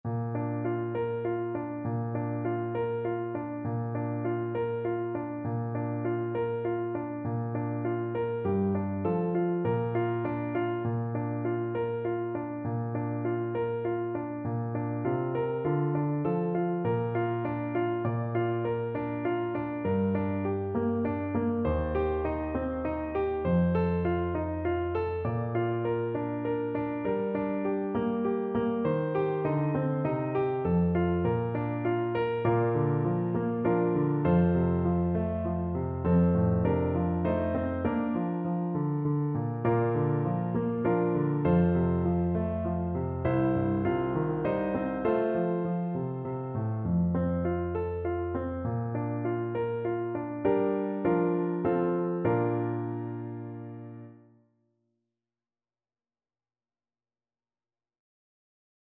Clarinet
Traditional Music of unknown author.
Bb major (Sounding Pitch) C major (Clarinet in Bb) (View more Bb major Music for Clarinet )
3/4 (View more 3/4 Music)
Moderato
F5-F6